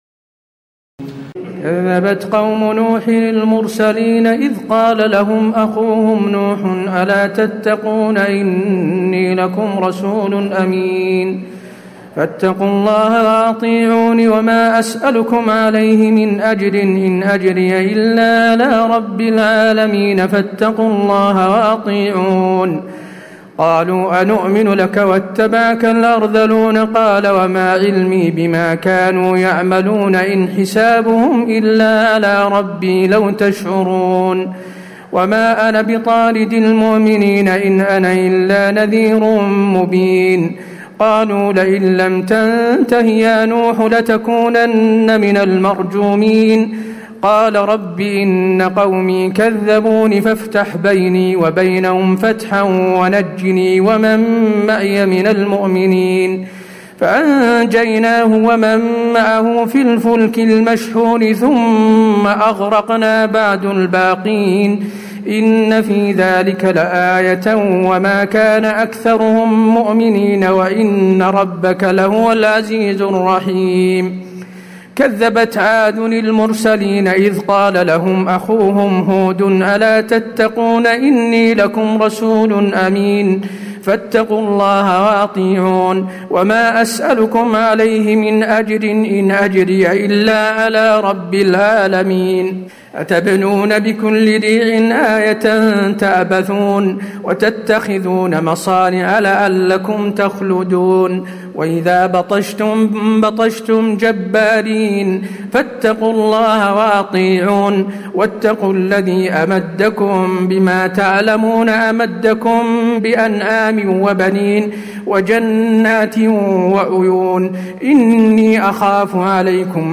تراويح الليلة الثامنة عشر رمضان 1436هـ من سورتي الشعراء (105-227) والنمل (1-59) Taraweeh 18 st night Ramadan 1436H from Surah Ash-Shu'araa and An-Naml > تراويح الحرم النبوي عام 1436 🕌 > التراويح - تلاوات الحرمين